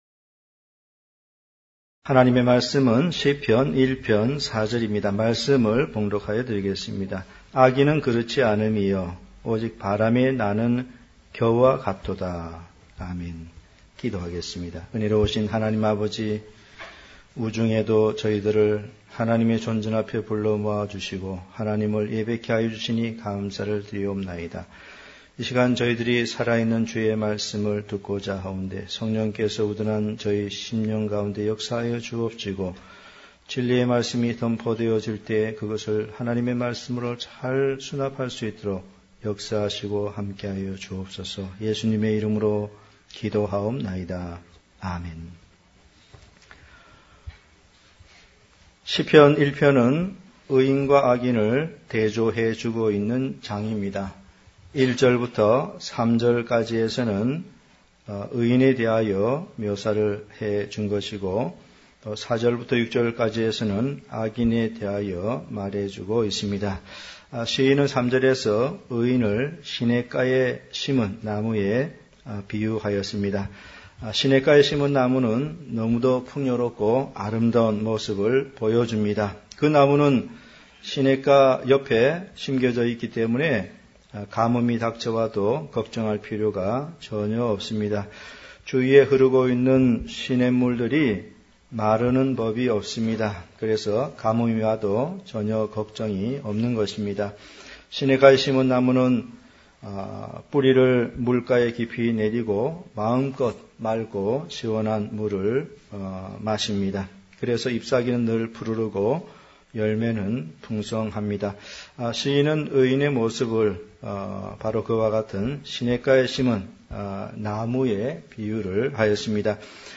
단편설교